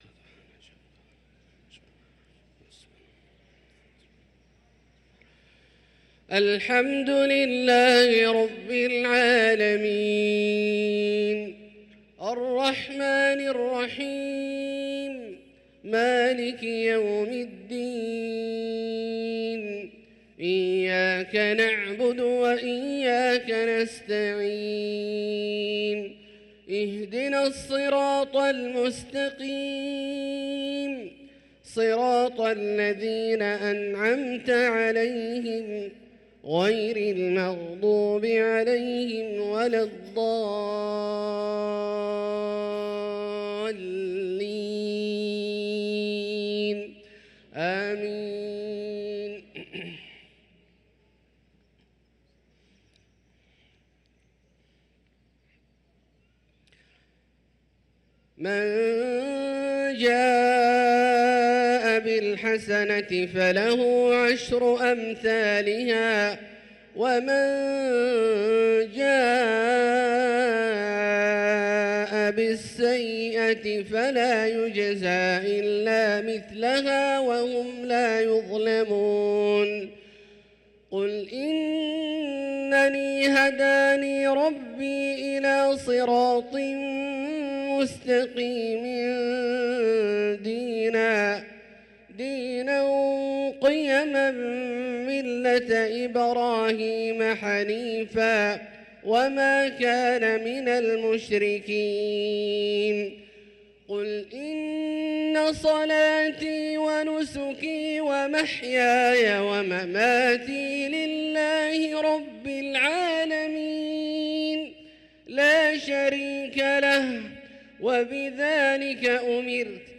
صلاة العشاء للقارئ عبدالله الجهني 23 جمادي الآخر 1445 هـ